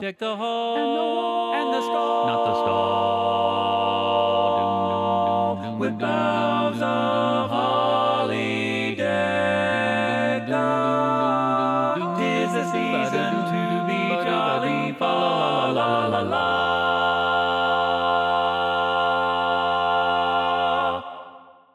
Key written in: F Major
Type: Barbershop